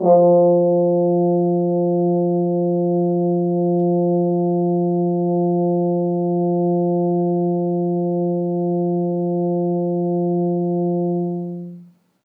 french-horn
F3.wav